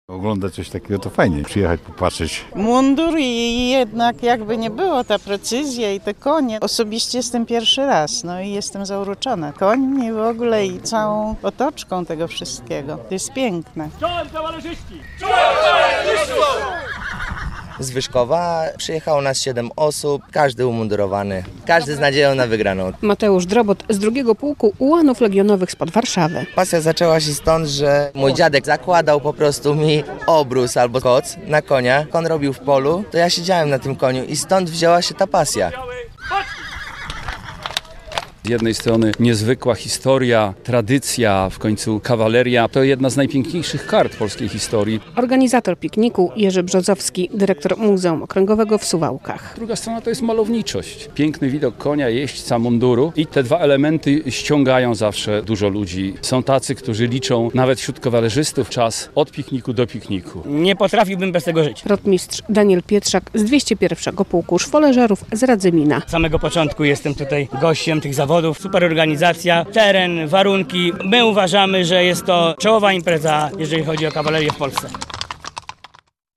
W Suwałkach rozpoczął się XXII Piknik Kawaleryjski - relacja